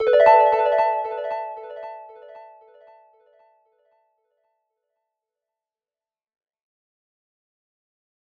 Echoes_A_01.wav